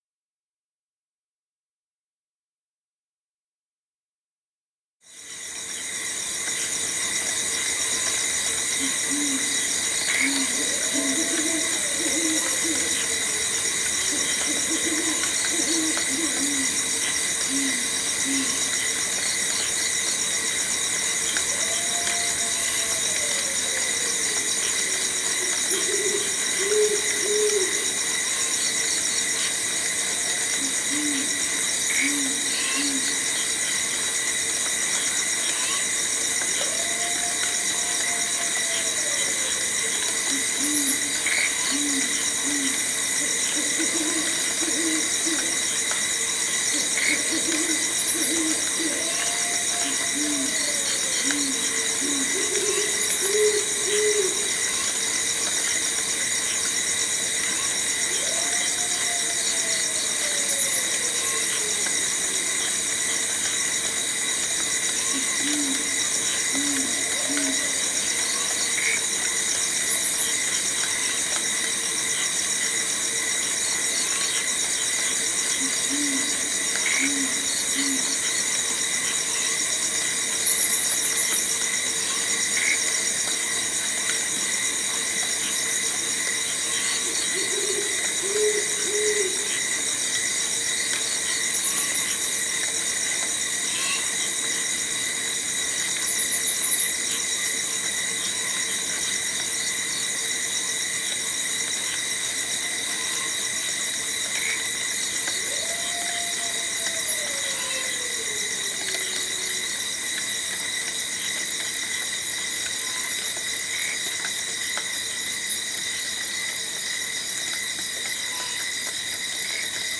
Receding Forest Night Nature Sound
Forest-Night-Dolby-15mins-MP3.mp3